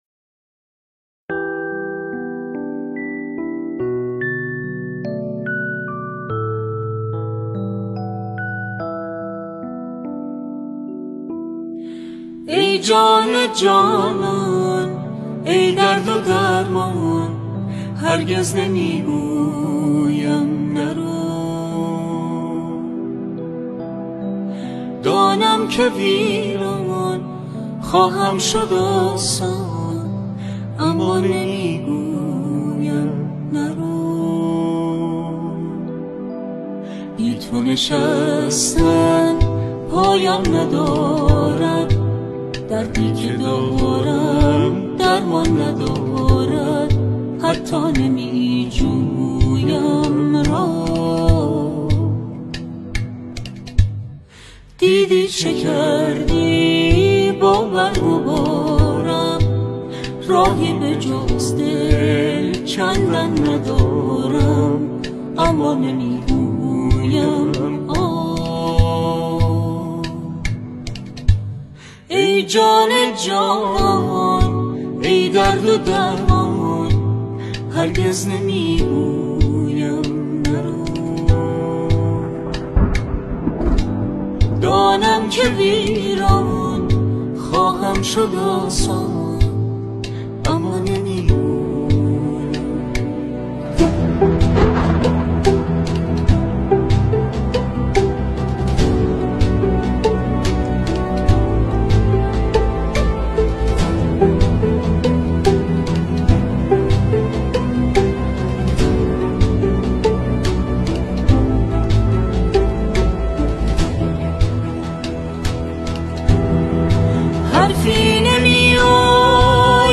10 تا قشنگترین آهنگ های غمگین جدید ایرانی